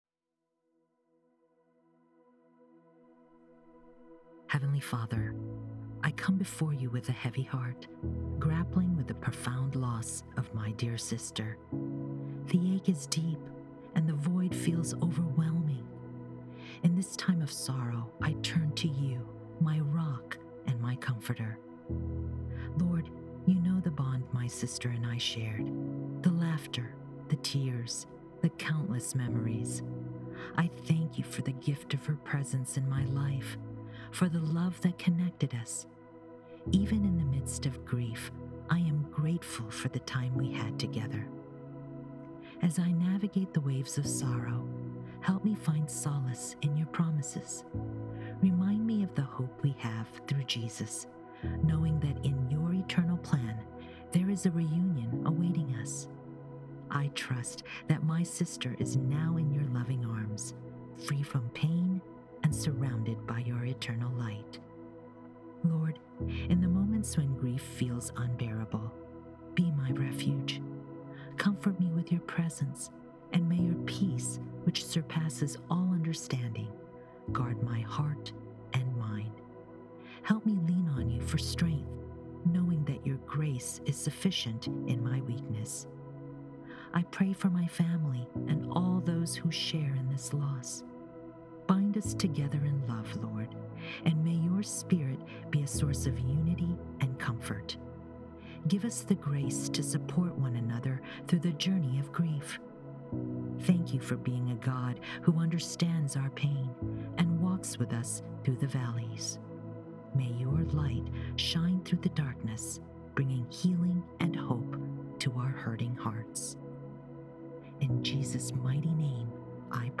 You are not navigating this path in isolation as you grieve the loss of your dear sister; let the shared love and support around you embrace your spirit. May these whispered words gently soothe your heart, bringing a measure of peace during this challenging time.